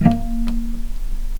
healing-soundscapes/Sound Banks/HSS_OP_Pack/Strings/cello/pizz/vc_pz-A#3-pp.AIF at 01ef1558cb71fd5ac0c09b723e26d76a8e1b755c - healing-soundscapes - Ligeti Zentrum Gitea
vc_pz-A#3-pp.AIF